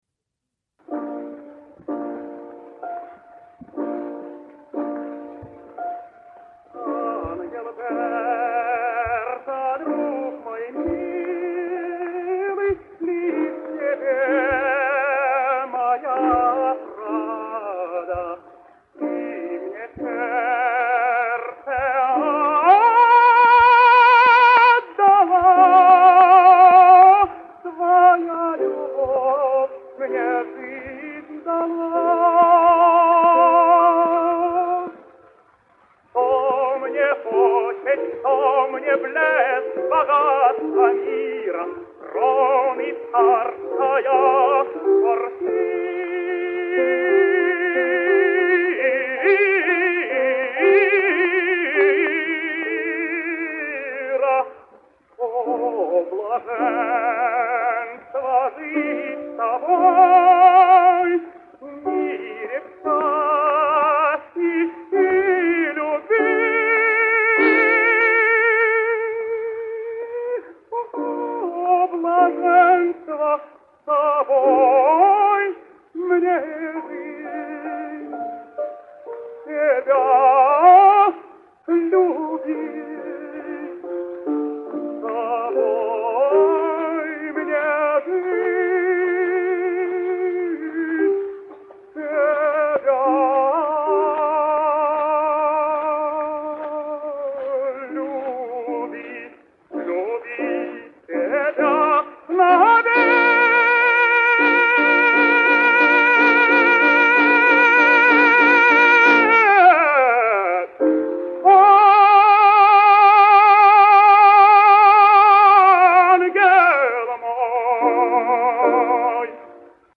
Russian Tenor.
One of the very few and certainly the greatest of Russian dramatic tenors was Ivan Yershov, born in Russia in 1867.
Fortunately, we hear him here in 1903, at his peak and in one of his greatest roles Mierbiere’s the Prophet.
His was a sturdy ringing heroic voice as we can hear in an excerpt from Tannhauser. But more than that, he could wield his powerful instrument with grace and elegance.